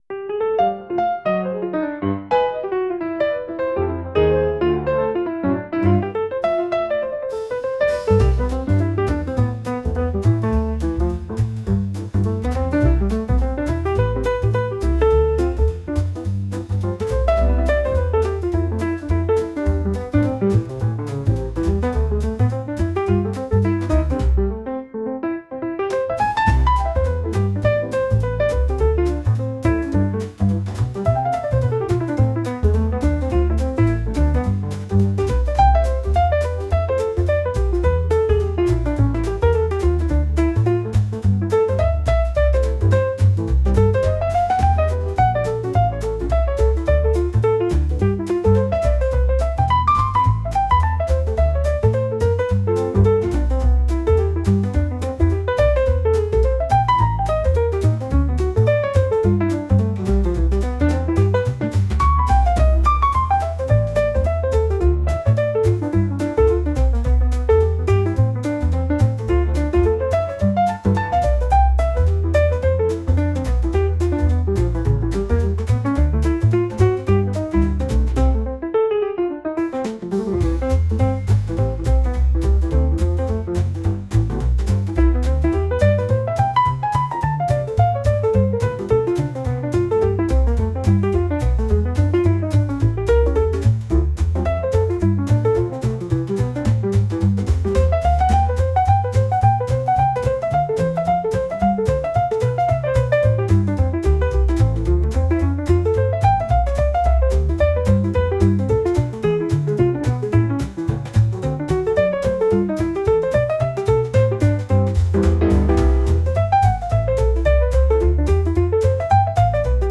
ジャンルJAZZ
楽曲イメージBar, カフェ, ムーディー,
シーン店舗BGM
ここは喧騒に満ちた夜のバー。
しかし私の意識は微かにしか聞こえないピアノの音に支配されていた。